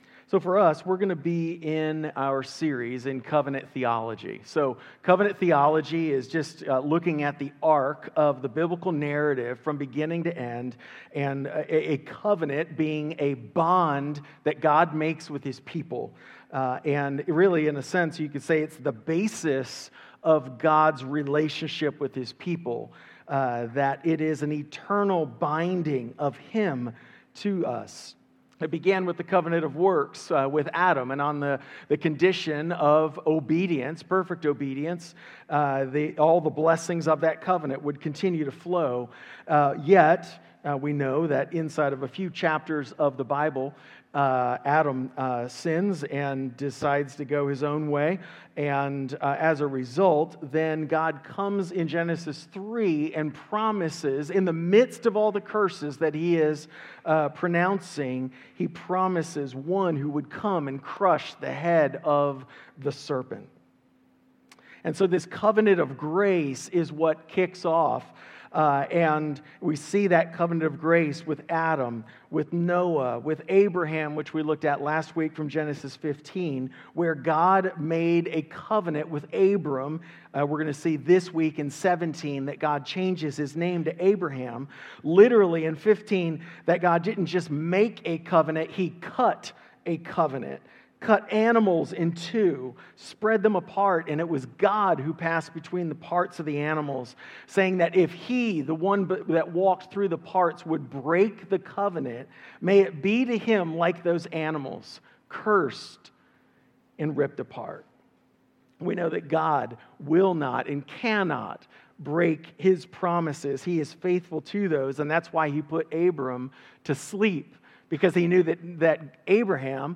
Sermons | Grace Point Church